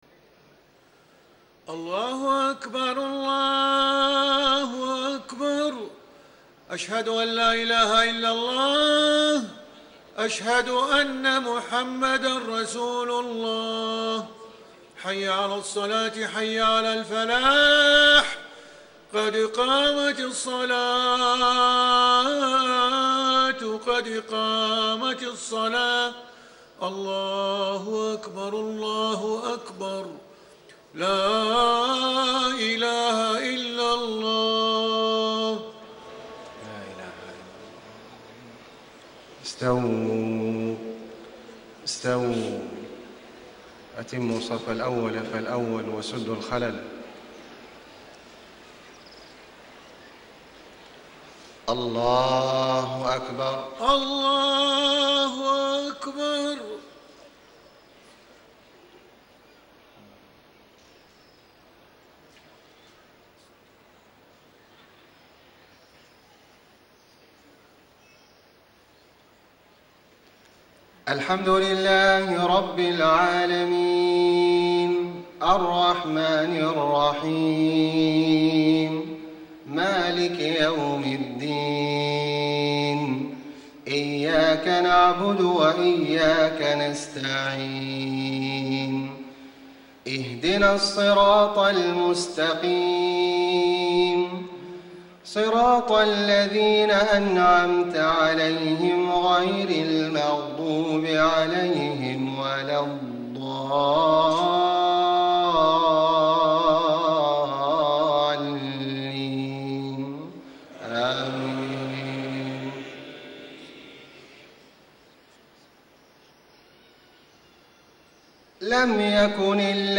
صلاة العشاء 3-5-1435 سورة البينة > 1435 🕋 > الفروض - تلاوات الحرمين